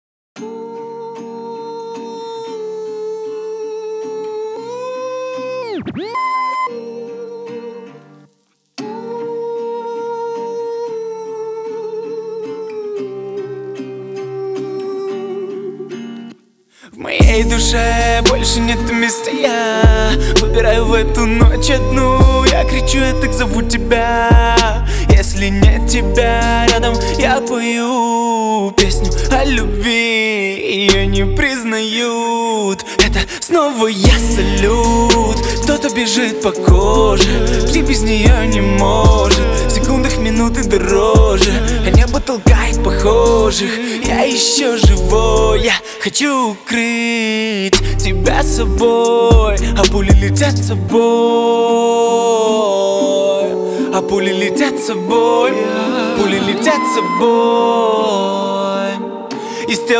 • Качество: 320, Stereo
поп
гитара
мужской вокал
грустные
спокойные